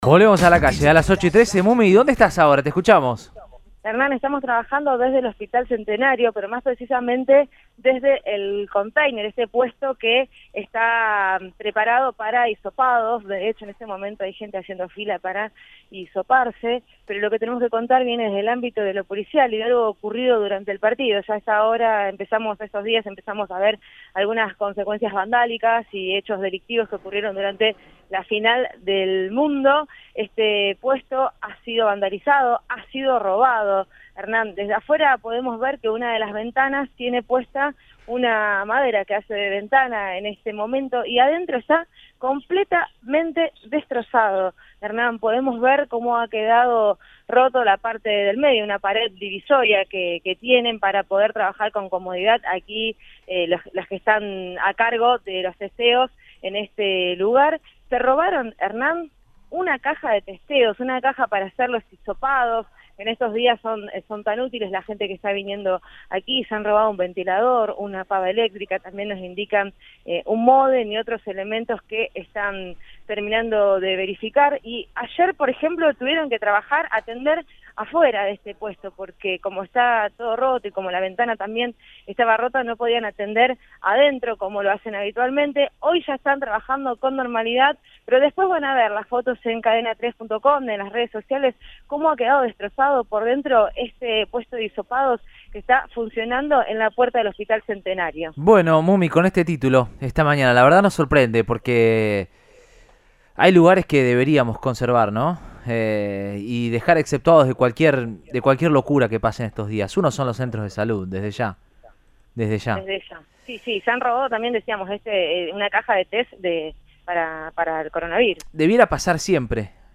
El móvil de Cadena 3 Rosario, en Radioinforme 3, informó que el hecho tuvo lugar el domingo.